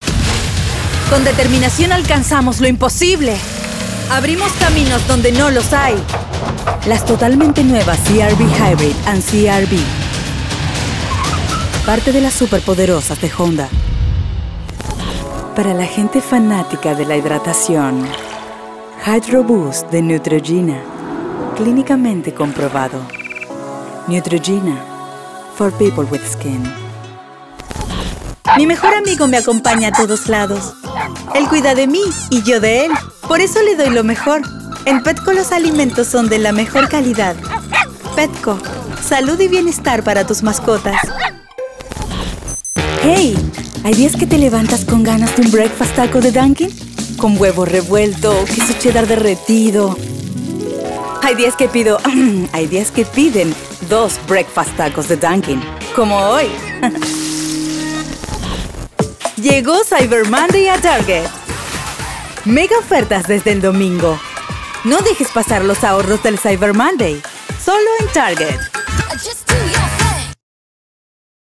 Commercial Spanish